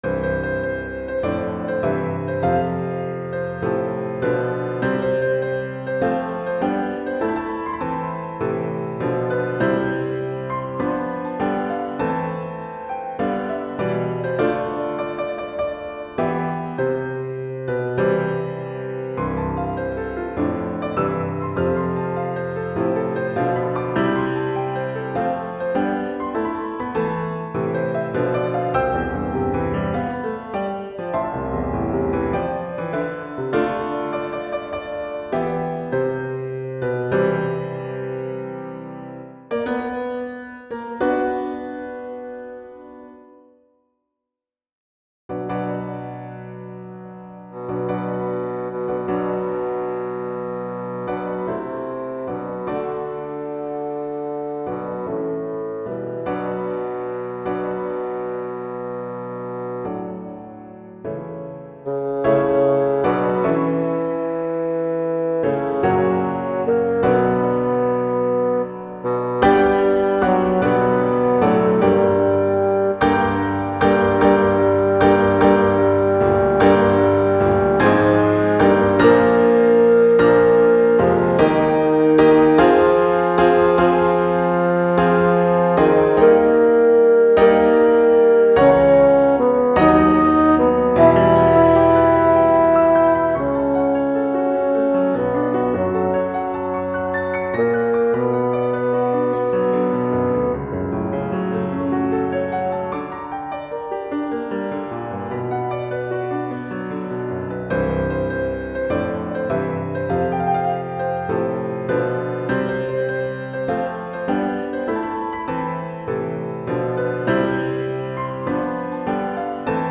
Tonart: C-Dur / Tempo: Andante
- In der Demoaufnahme wurde die Gesangstimme durch ein Fagott ersetzt.